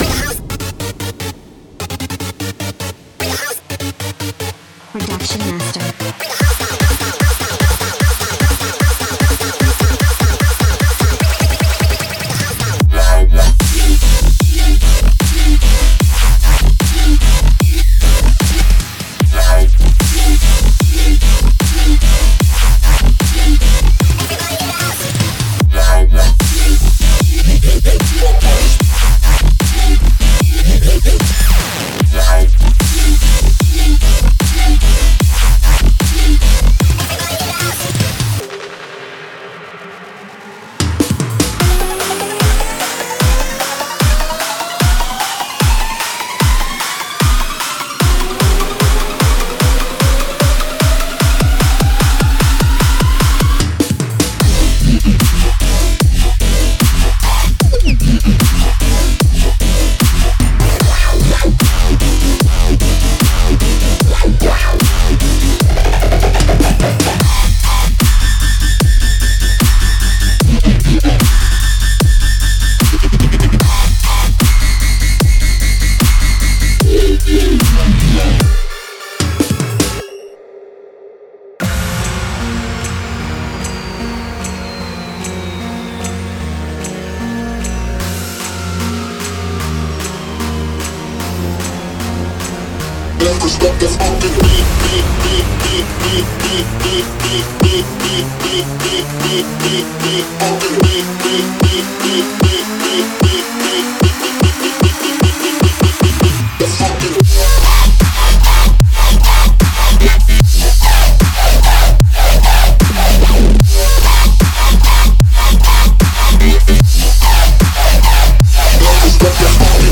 我们将返回第二个最肮脏，最讨厌的dubstep声音。
狂暴的鼓声：在这个疯狂的Riddim dubstep包中，期望有最平均，最重的击打，粉碎的网罗，凶狠的拍手和雷鸣的帽子。
令人讨厌的低音和受鼓舞的合成器：利用这些令人毛骨悚然的低音和充满活力的合成器循环，优化制作最深的深度。